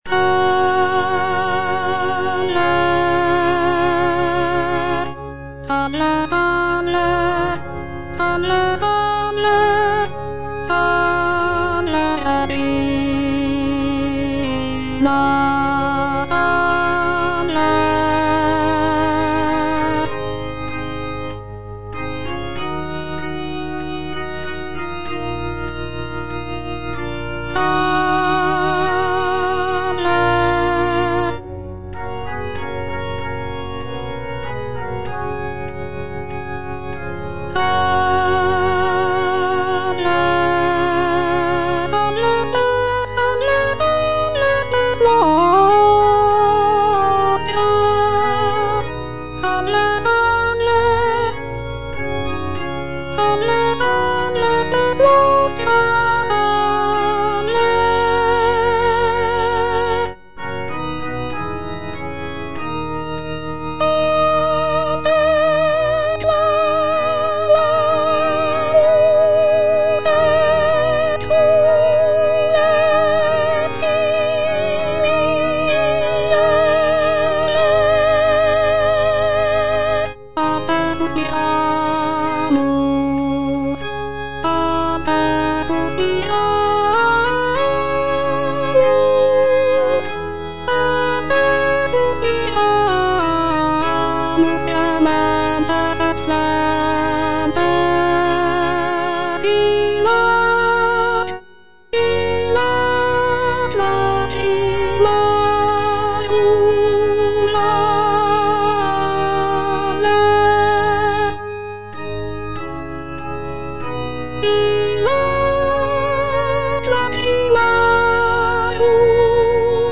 G.F. SANCES - SALVE, REGINA (A = 415 Hz) Soprano (Voice with metronome) Ads stop: auto-stop
This piece is a setting of the traditional Latin Marian hymn "Salve Regina" and is performed in a lower pitch of A = 415 Hz, which was common in the Baroque era. The work features rich harmonies, expressive melodies, and intricate vocal lines, showcasing Sances' skill as a composer of sacred music.